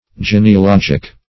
Genealogic \Gen`e*a*log"ic\, a.
genealogic.mp3